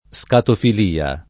scatofilia [ S katofil & a ] s. f.